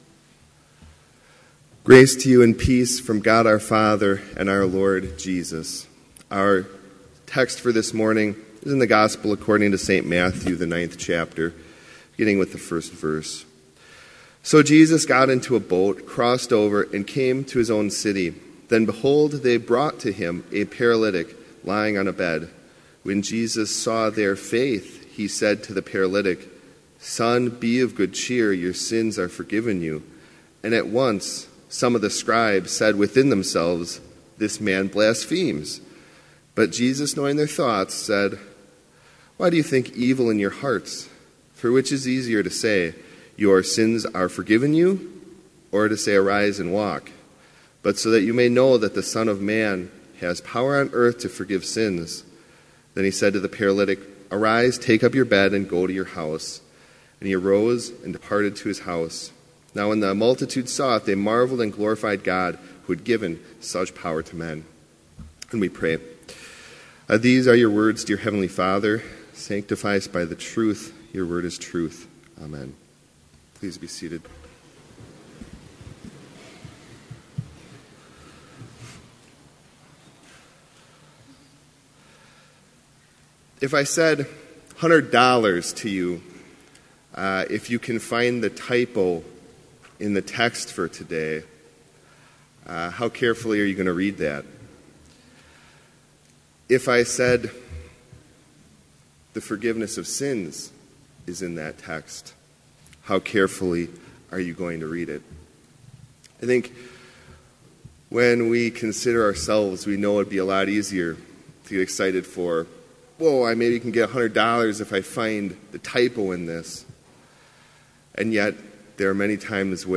Complete service audio for Chapel - October 9, 2018